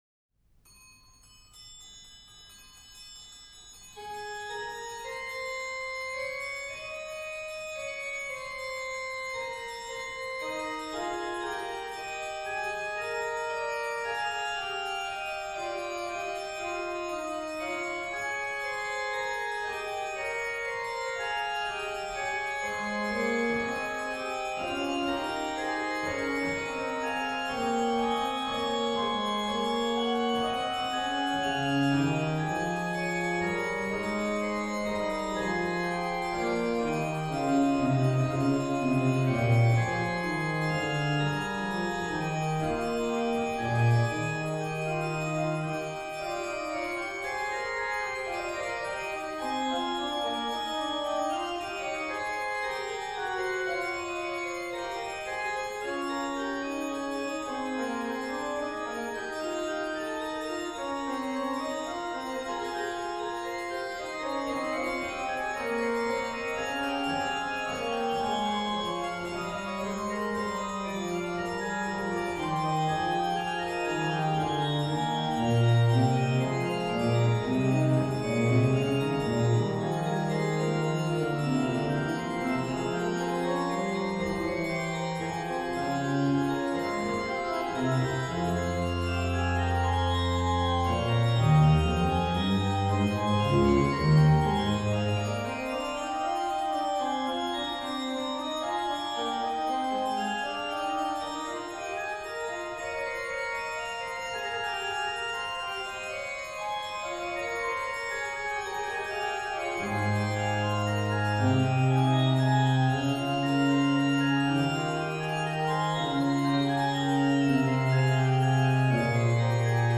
Venue   1724-30 Trost organ, Stadtkirche, Waltershausen, Germany
Registration   rh: BW: Ged8, Gms4, Oct2
lh: HW: Gms8, Viol8, Oct4
Ped: Oct8, Tr8
Cymbel-Stern in G